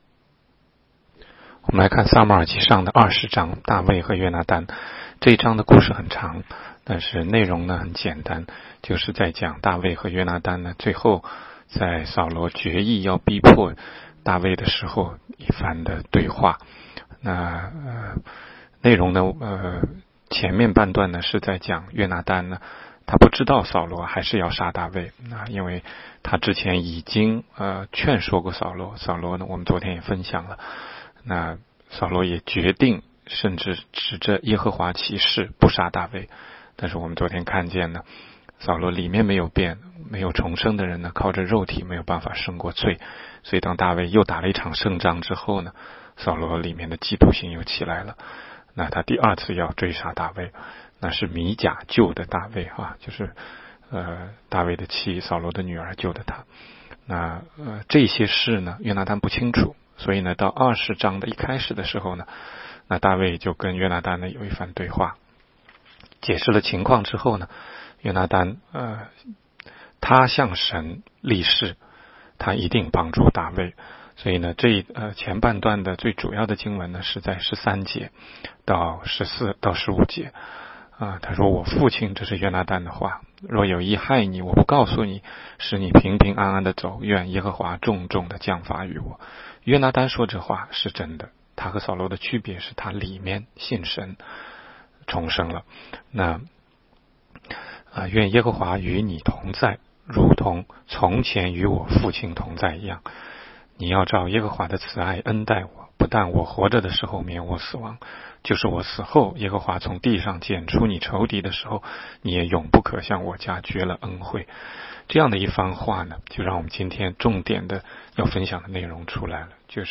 16街讲道录音 - 每日读经-《撒母耳记上》20章
每日读经